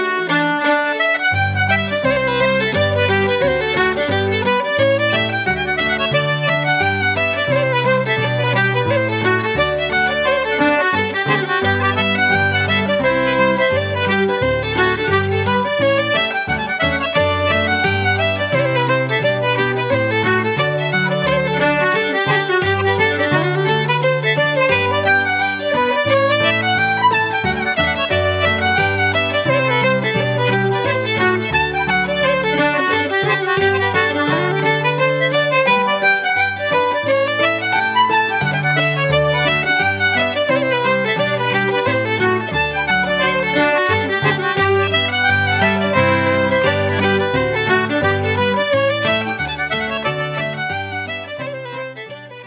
Fiddle and concertina from County Meath.